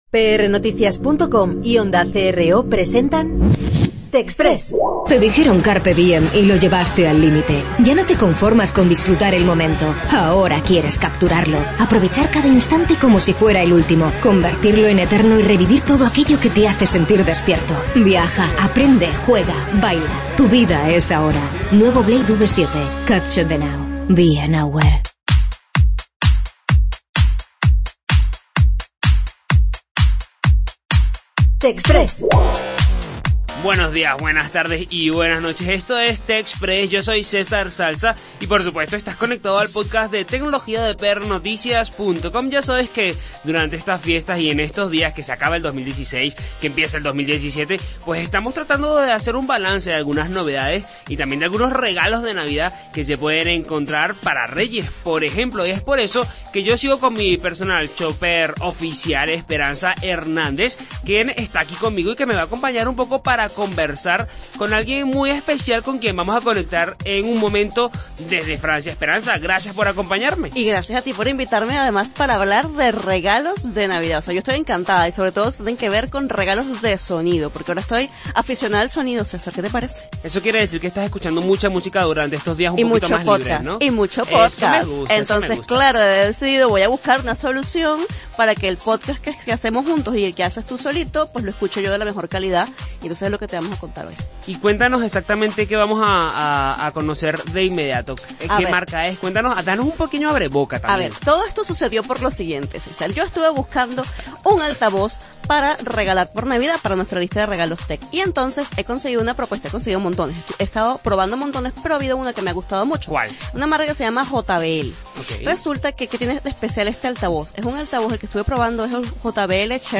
Escucha la entrevista completa en el podcast.